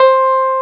CLAV2SFTC5.wav